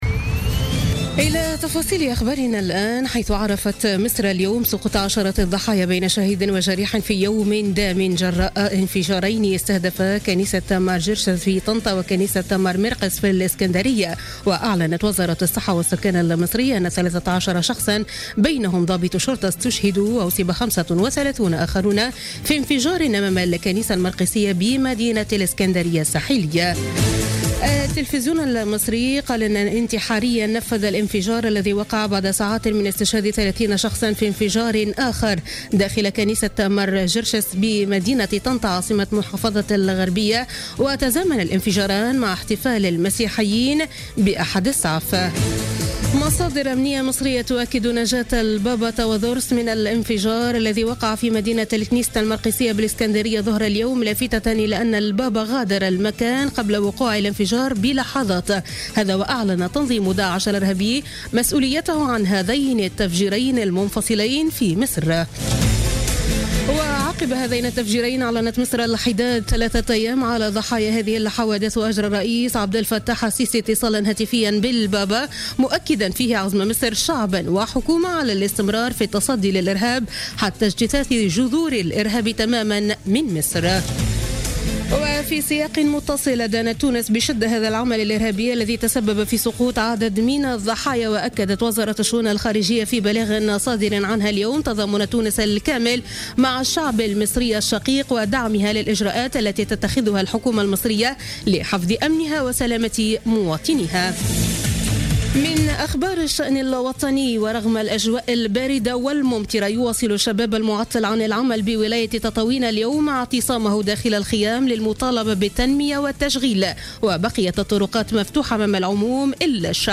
نشرة أخبار السابعة مساء ليوم الأحد 9 أفريل 2017